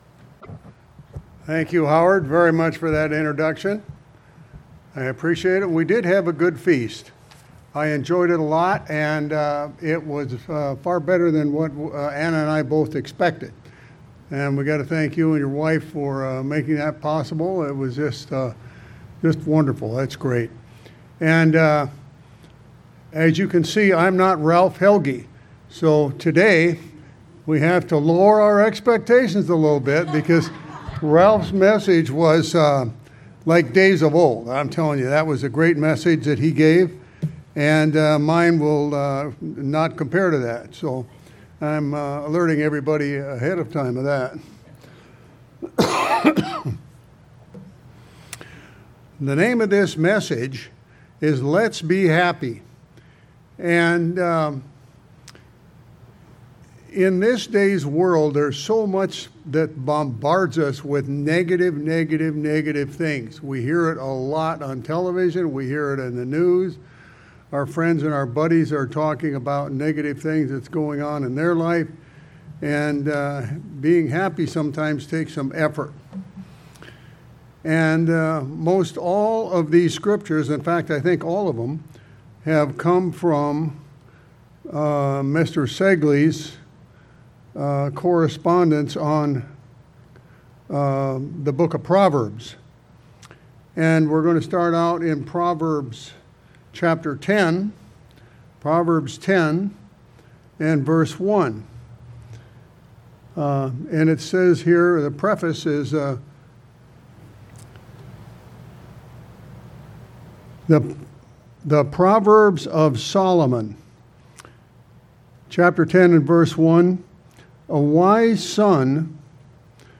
Sermons
Given in Orange County, CA